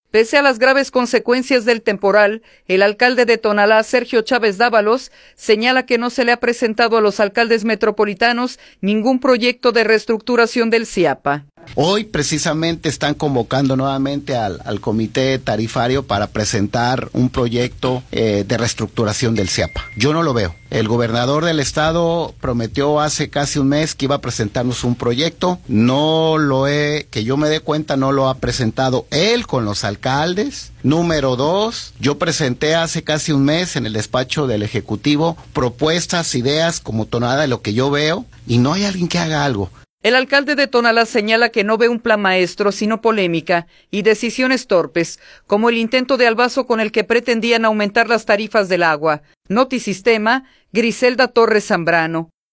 Pese a las graves consecuencias del temporal, el alcalde de Tonalá, Sergio Chávez Dávalos, señala que no se le ha presentado a los alcaldes metropolitanos ningún proyecto de restructuración del SIAPA.